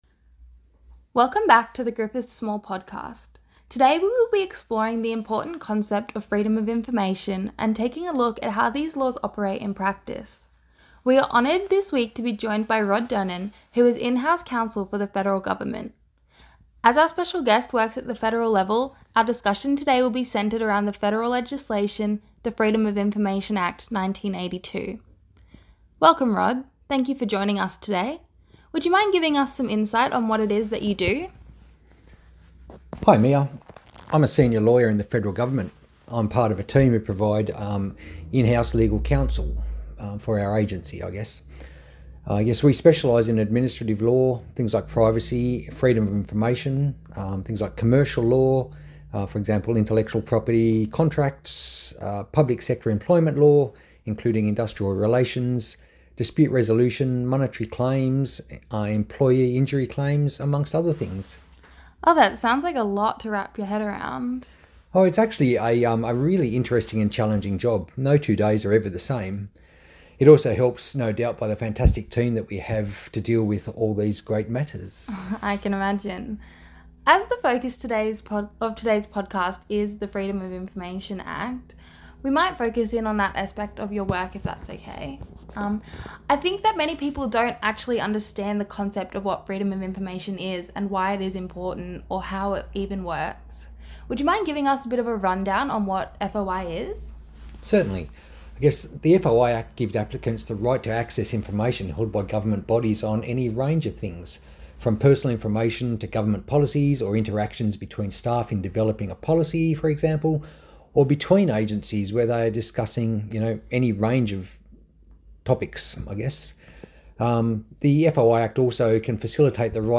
Welcome to Griffith SMALL (Social Media And Law Livestream) where we interview experts on a range of aspects of social media law.